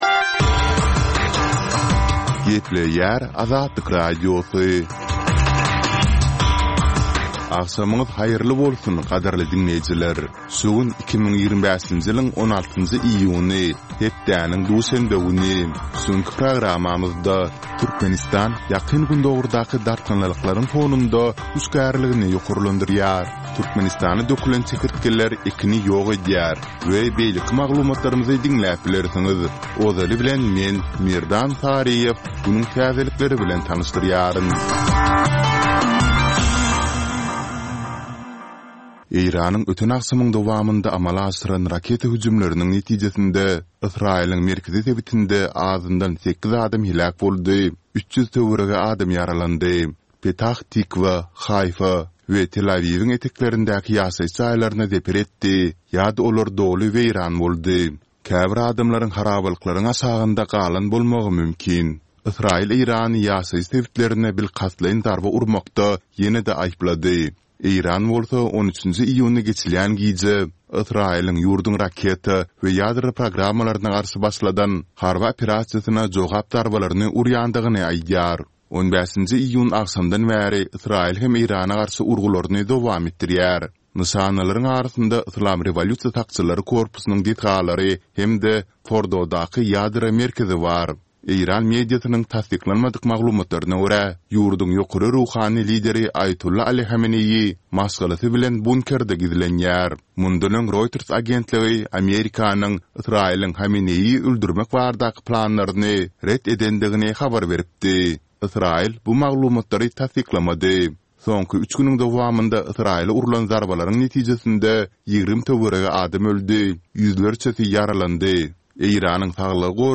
Täzelikler